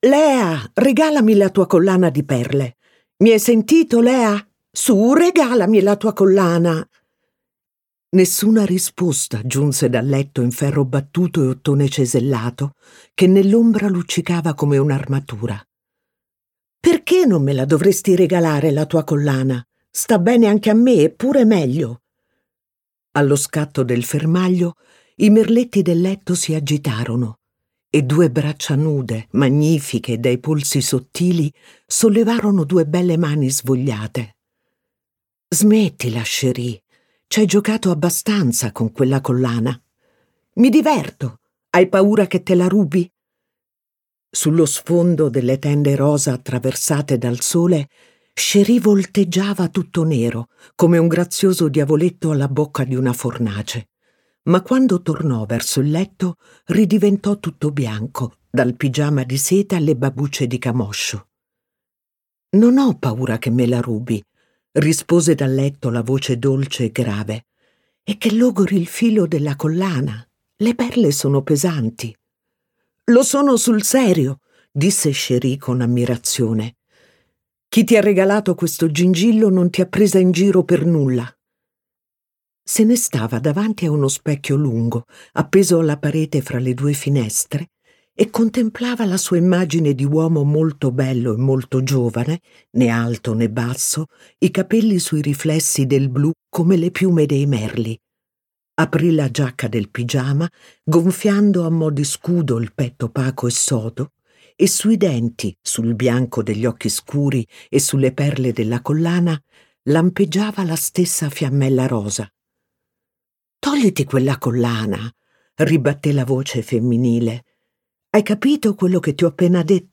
letto da Iaia Forte
Versione audiolibro integrale